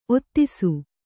♪ ottisu